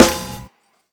HJK_SNR.wav